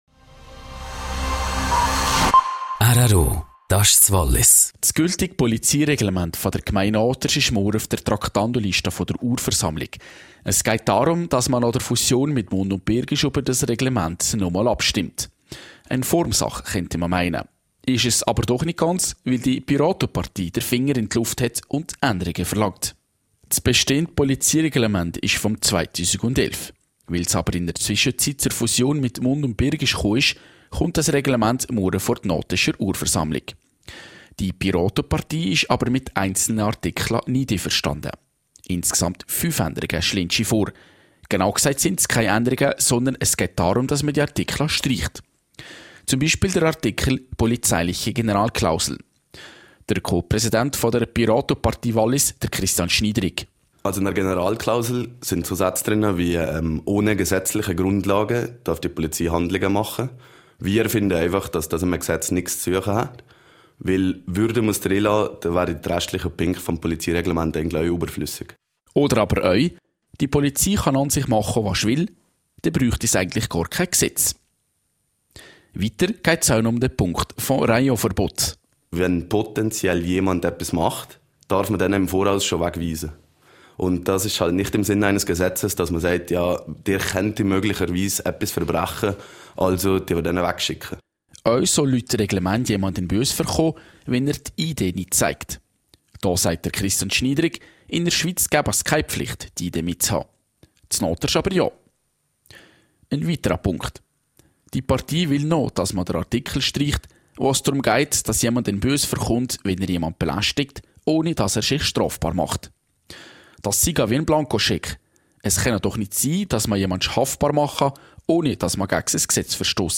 6234_News.mp3